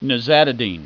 Pronunciation
(ni ZA ti deen)